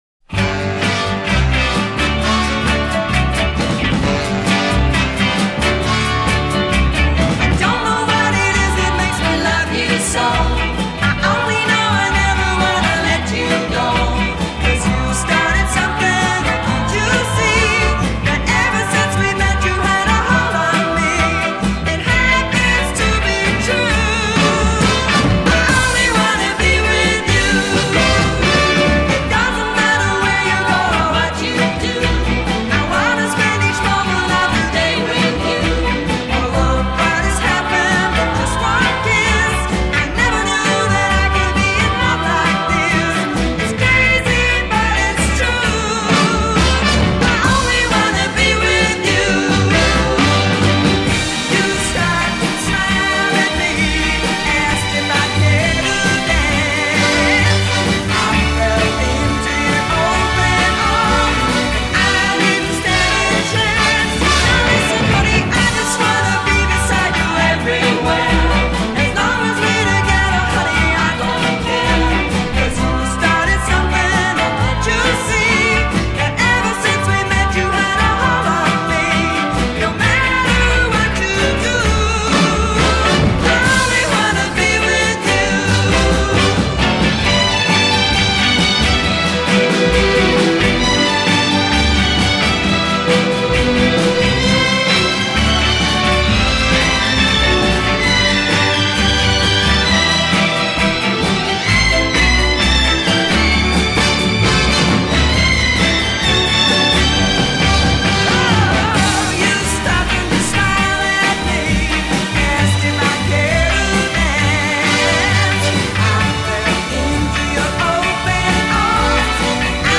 bass
guitar
drums
A Verse 0:07   Solo vocal with responding vocal group. a
B Chorus 0:50   Drop singers. Add strings. c